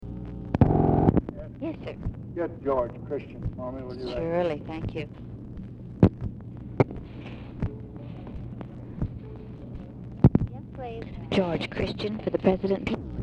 Telephone conversation # 10794, sound recording, LBJ and OFFICE SECRETARY, 9/17/1966, time unknown | Discover LBJ
Format Dictation belt
Location Of Speaker 1 Oval Office or unknown location